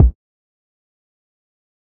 SU_Kick (Moon Rocks)(1).wav